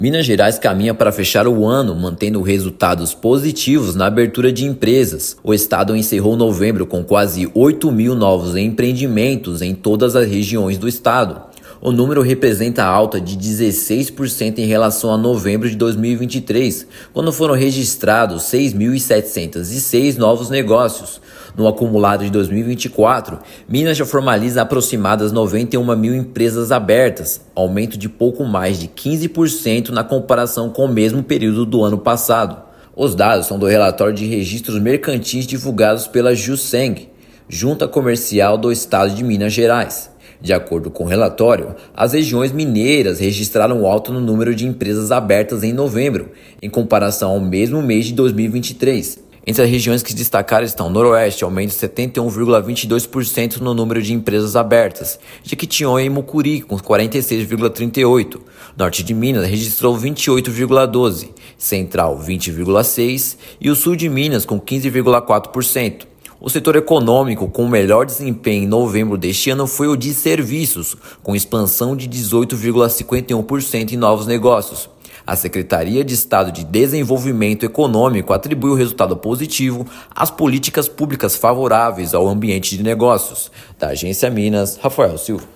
No acumulado do ano, estado registra 91.613 novos negócios, alta de 15,43% em relação ao mesmo período de 2023. Ouça matéria de rádio.